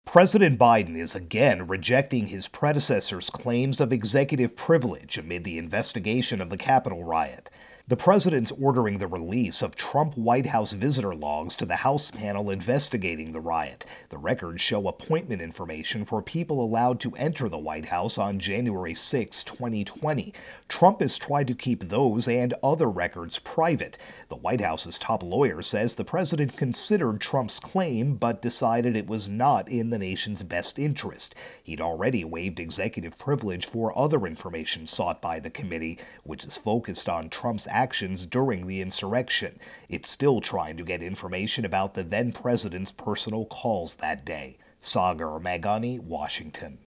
Capitol Riot-Investigation intro and voicer.